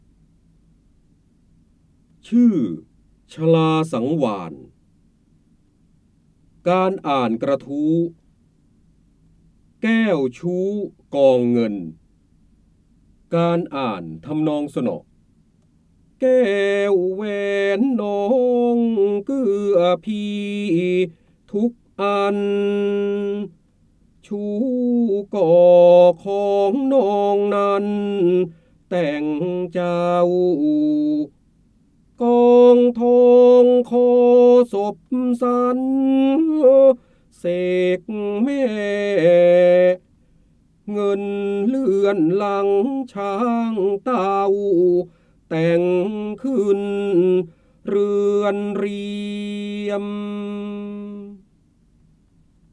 เสียงบรรยายจากหนังสือ จินดามณี (พระโหราธิบดี) ชื่อชลาสังวาล
คำสำคัญ : ร้อยกรอง, พระเจ้าบรมโกศ, จินดามณี, พระโหราธิบดี, ร้อยแก้ว, การอ่านออกเสียง